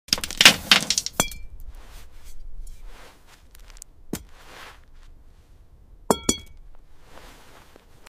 ASMR glass garden vegetables, Onion